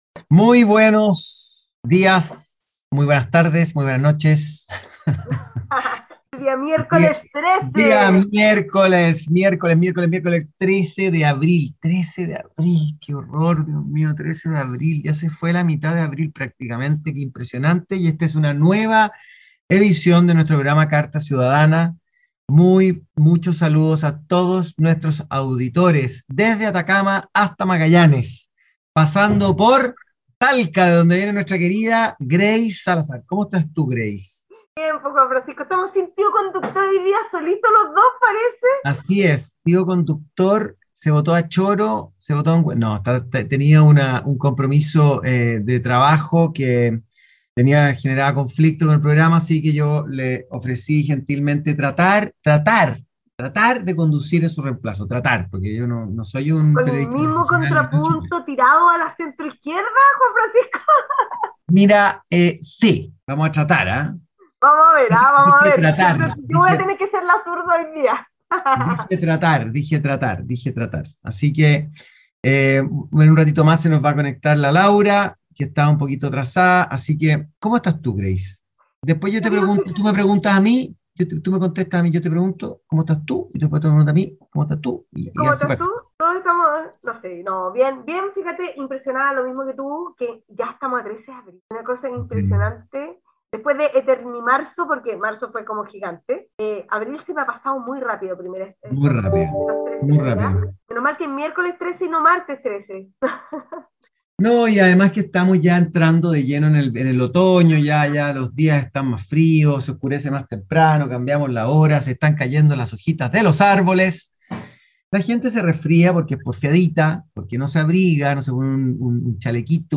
programa de conversación y análisis de la contingencia en Chile.